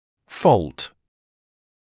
[fɔːlt][фоːлт]дефект; недостаток; порок; неисправность; вина